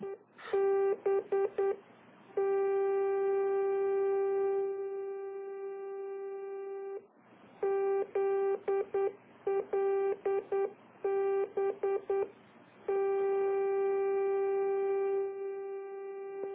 NDB Sounds
ZLB - 236 kHz (Toronto, Ontario) - Recorded after the transmitter was removed from site while sitting on a workbench.